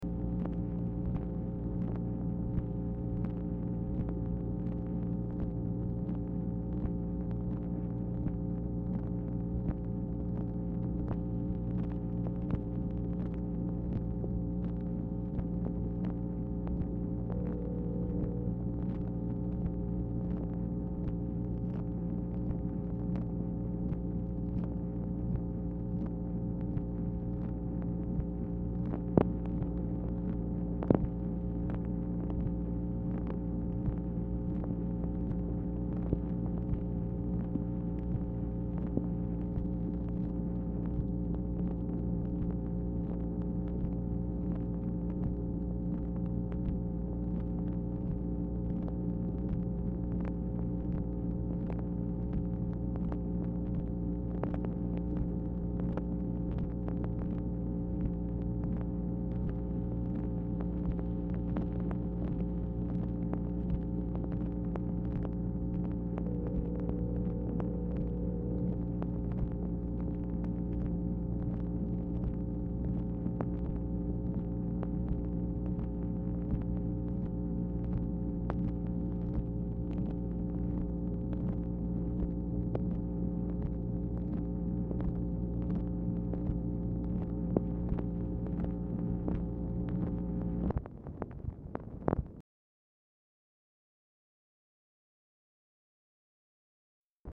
Telephone conversation # 11007, sound recording, MACHINE NOISE, 11/3/1966, time unknown | Discover LBJ
Telephone conversation
Dictation belt